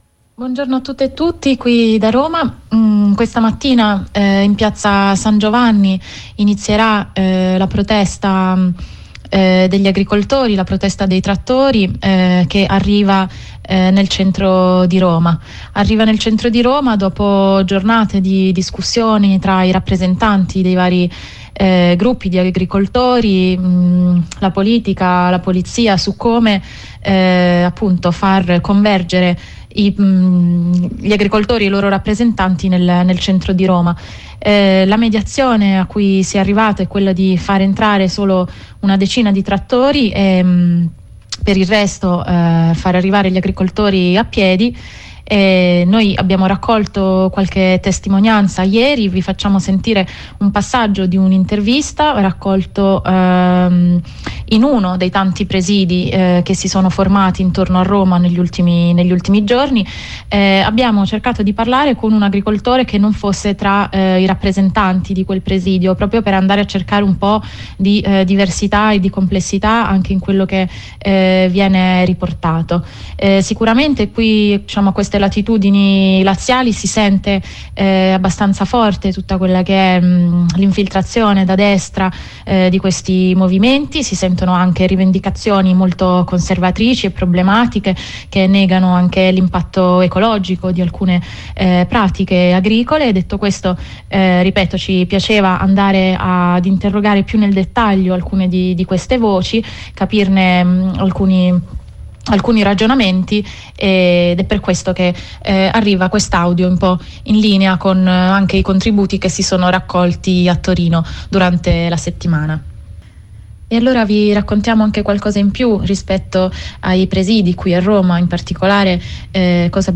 Continua la protesta dei “trattori” e anche gli approfondimenti da parte della nostra radio su i nodi e contraddizioni di un conflitto, che tocca temi centrali del dibattito pubblico – la produzione agricola, i sistemi di produzione e distribuzione, il tema ambientale e le politiche green portate avanti in sede europea. Oggi grazie ad una corrispondenza da Roma, riportiamo impressioni e voci dirette degli agricoltori in agitazione.